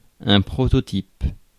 Ääntäminen
Synonyymit modèle original parangon archétype proto type maquette Ääntäminen France Tuntematon aksentti: IPA: /pʁɔ.tɔ.tip/ Haettu sana löytyi näillä lähdekielillä: ranska Käännöksiä ei löytynyt valitulle kohdekielelle.